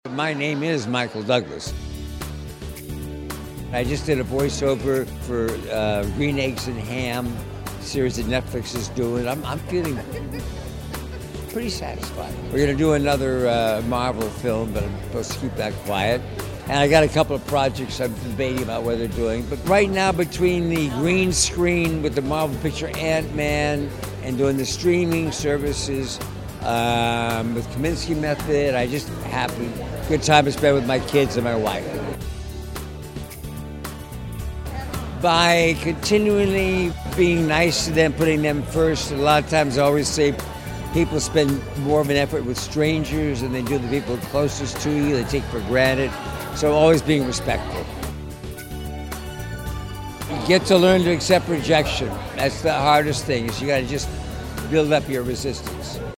Michael Douglas is a man of many roles. We met up with him at 2019 PaleyFest NY where he was to talk about the hit Netflix show The Kominsky Method, which he stars in.